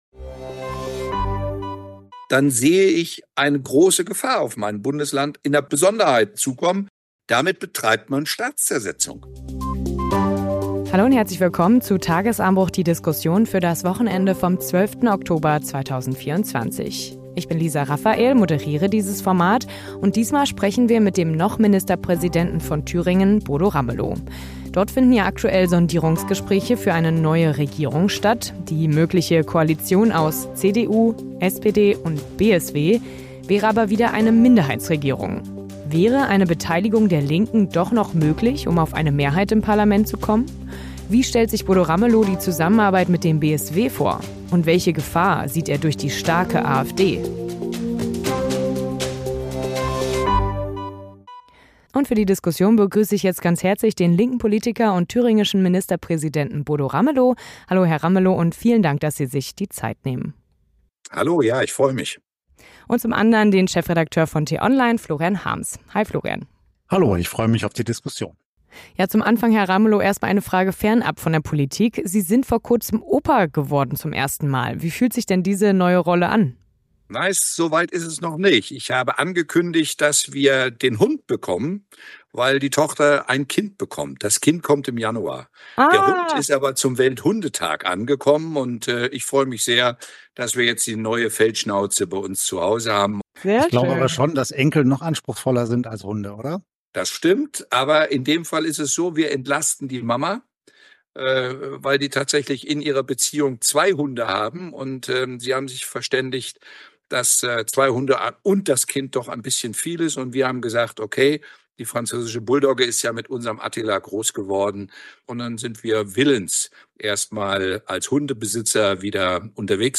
Unsere Reporter sprechen vor Ort in München mit: Lars Klingbeil (SPD) über europäische Stärke und den Umgang mit Trump. Thomas Erndl (CSU) über NATO, Aufrüstung und nukleare Abschreckung.